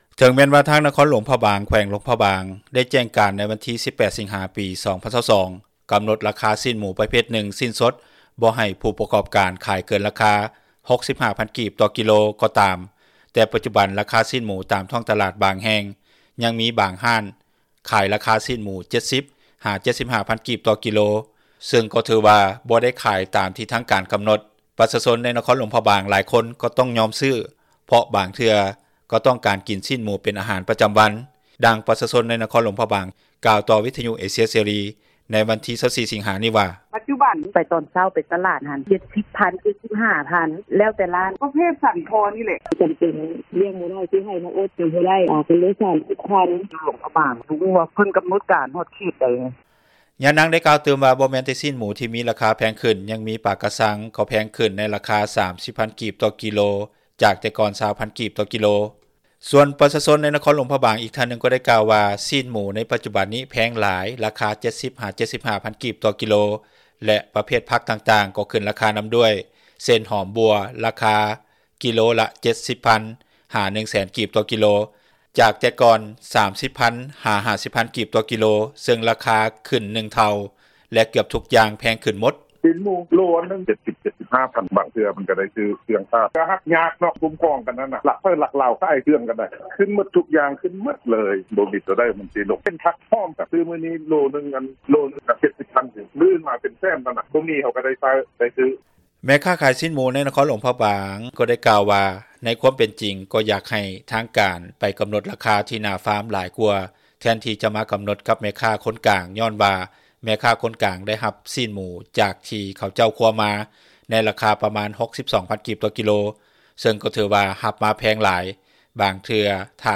ດັ່ງປະຊາຊົນ ໃນນະຄອນຫຼວງພຣະບາງ ກ່າວຕໍ່ວິທຍຸເອເຊັຽເສຣີ ໃນວັນທີ 24 ສິງຫາ ນີ້ວ່າ:
ດັ່ງເຈົ້າໜ້າທີ່ອຸດສາຫະກັມ ແລະການຄ້າ ໃນແຂວງຫຼວງພຣະບາງ ກ່າວໃນມື້ດຽວກັນນີ້ວ່າ: